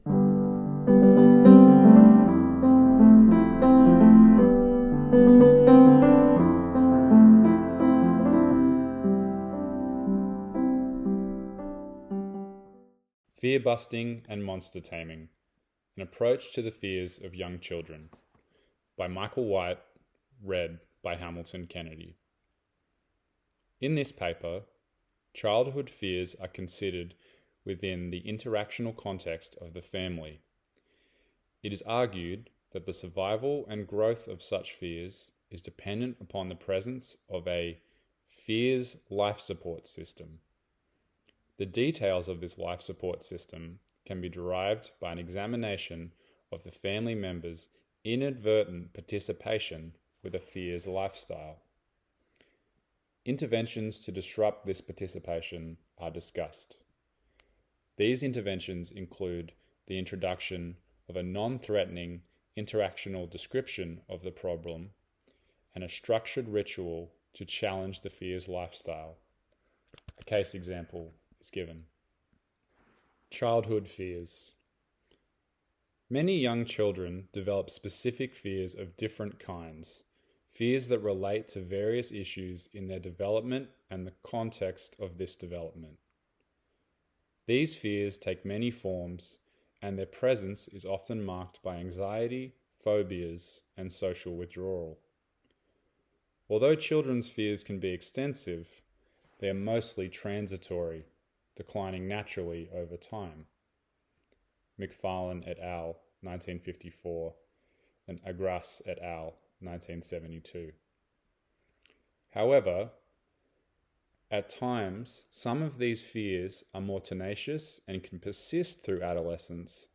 This is an audio recording of a paper that was originally published in Dulwich Centre Review, a precursor to this journal, in 1985. In this paper, childhood fears are considered within the interactional context of the family.